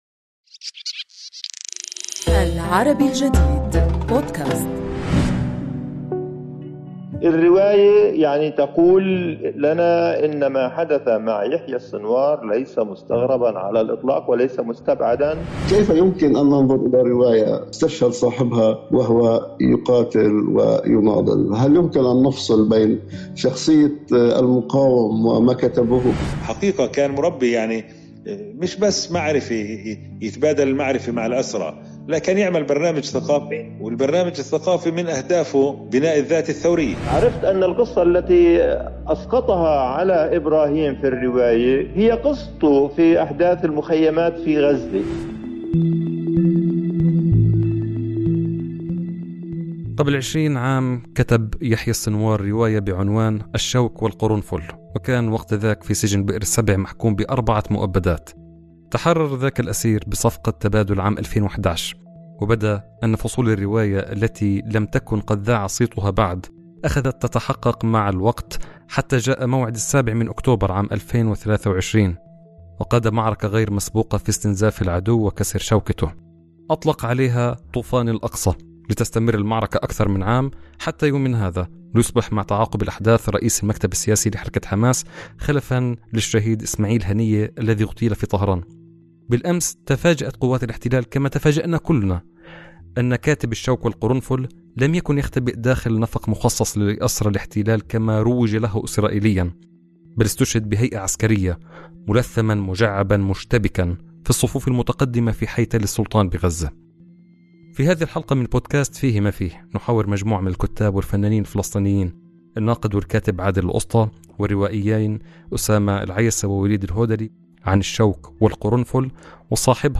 نحاور ثلاثةً من الكُتّاب الفلسطينيين